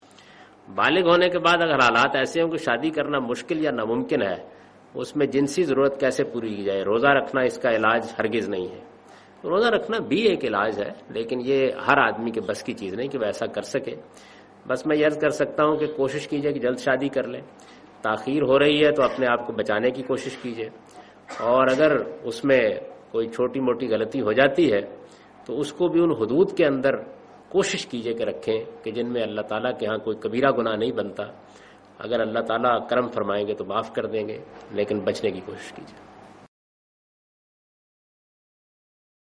Javed Ahmad Ghamidi responds to the question 'How one should handle one's desires if unable to marry'?